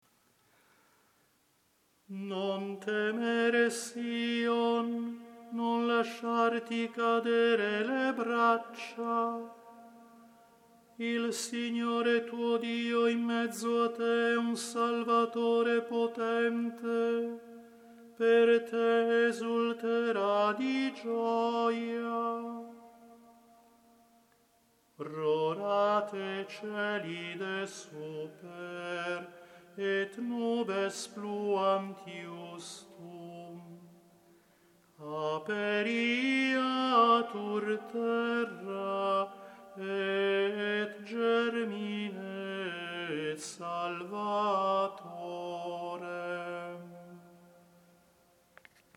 Si propone un tono salmodico ad hoc per ogni domenica di Avvento, che possa ben adattarsi alla semplice e magnifica antifona ambrosiana Rorate Coeli.